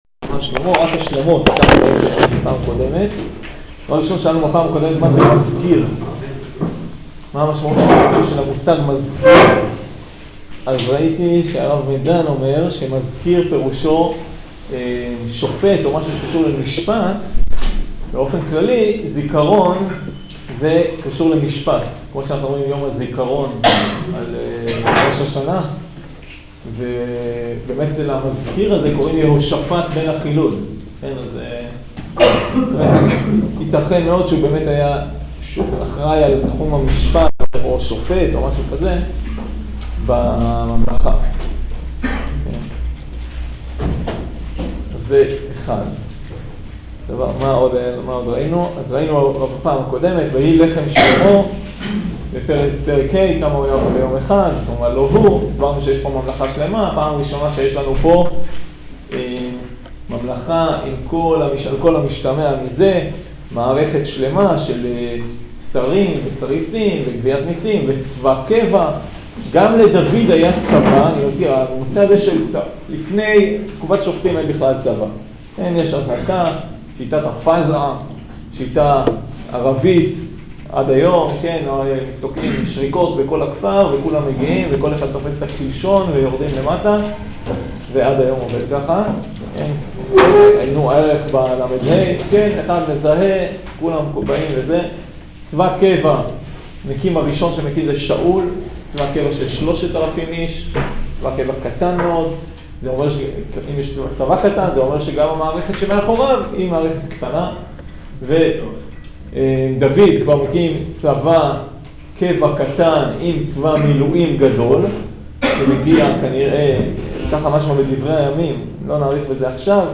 שיעור פרק ה